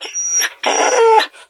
bdog_hurt_1.ogg